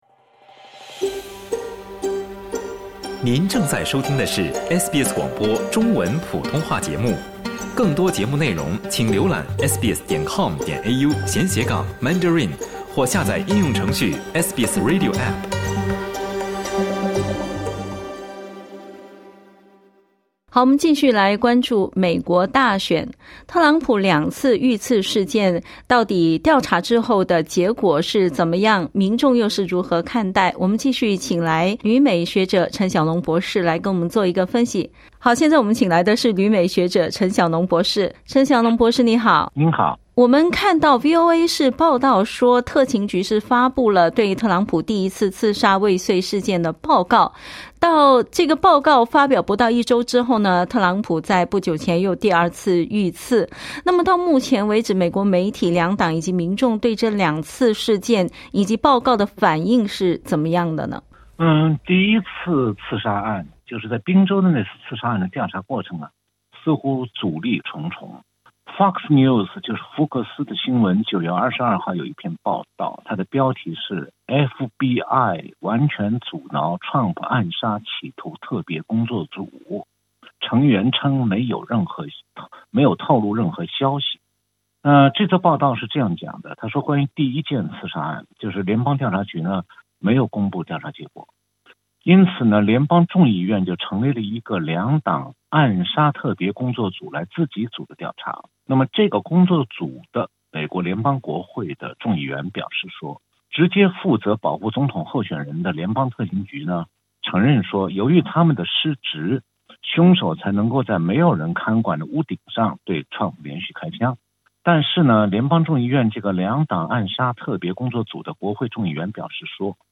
（点击音频收听详细采访） VOA报道，7月13日特朗普遇刺未遂事件调查：特勤局“自满”并多次失误。